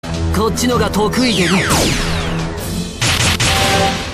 Play, download and share Merlin2 original sound button!!!!